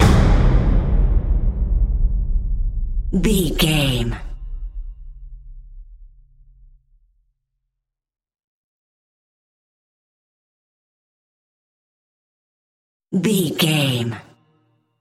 Huge Drum Hit.
Big Drum Hit
Sound Effects
Atonal
ominous
drums
percussion